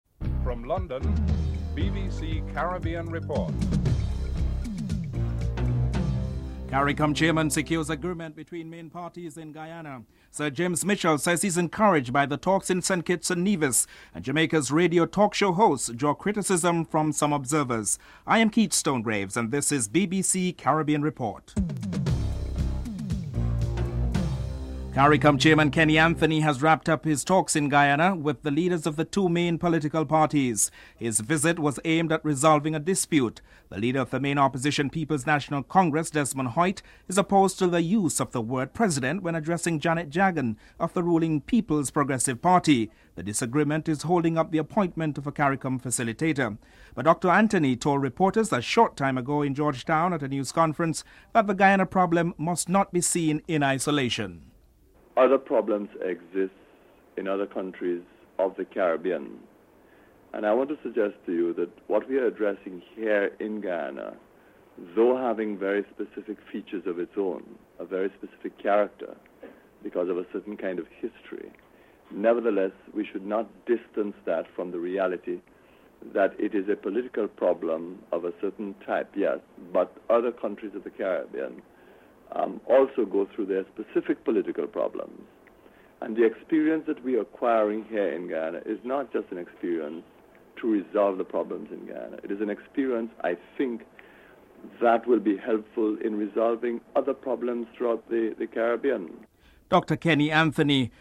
2. Caircom chairman secures an agreement between the main parties in Guyana. Prime Minister Kenny Anthony is interviewed (00:25-02:59)
Prime Minister Keith Mitchell is interviewed (07:03-07:59)